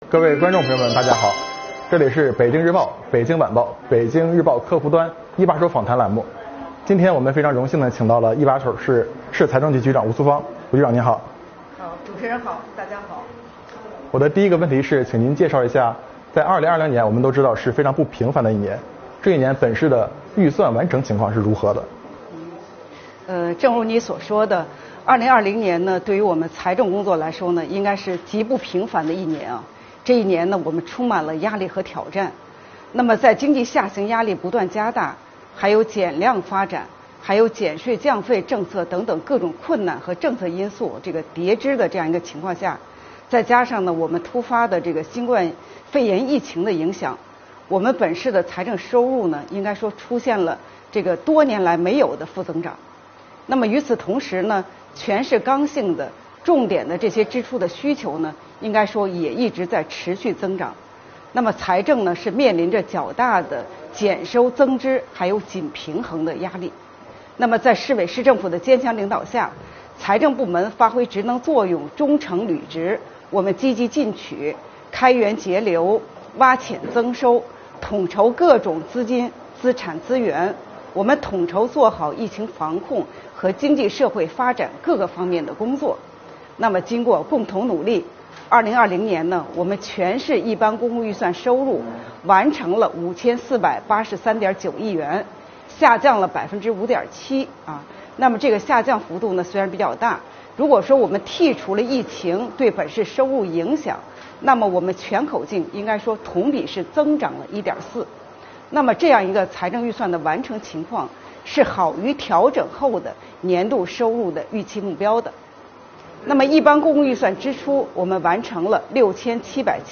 【一把手访谈】保障好全市各项重大支出和民生领域资金需求